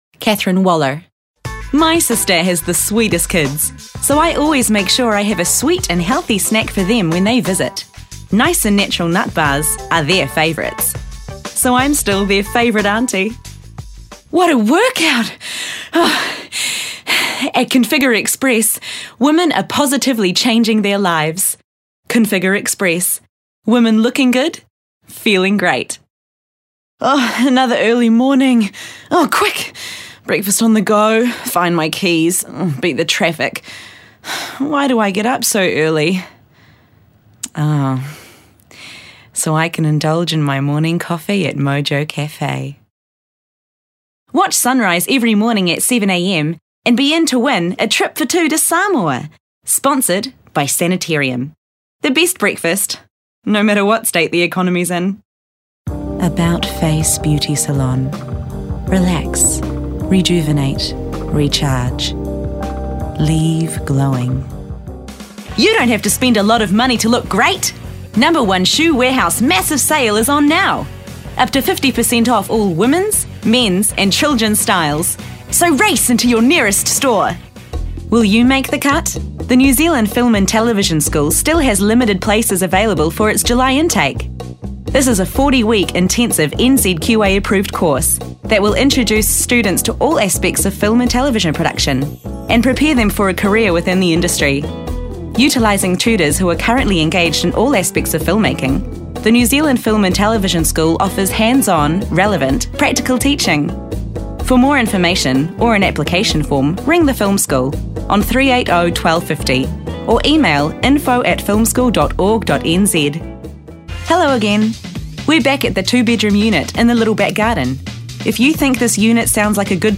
Adult, Young Adult
Has Own Studio
English | New Zealand
commercial